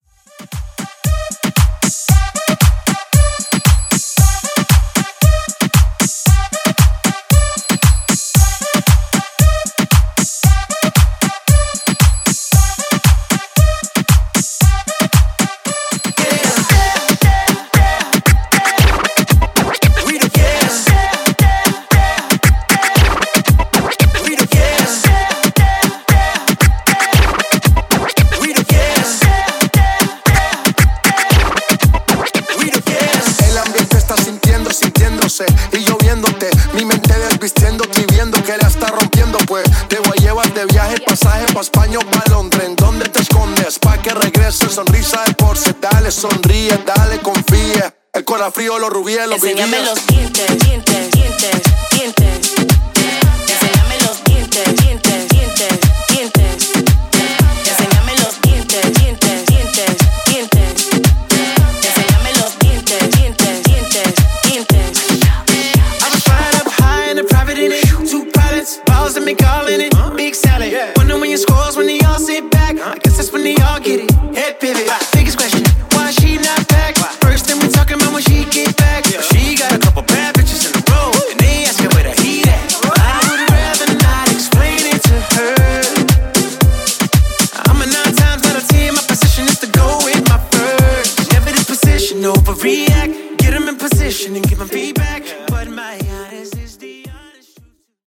Urbano Mixshow)Date Added